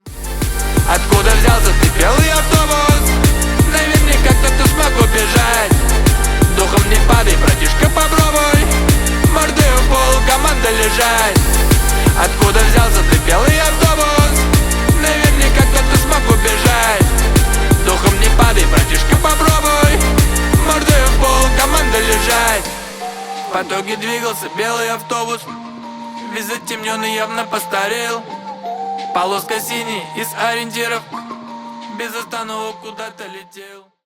клубные # громкие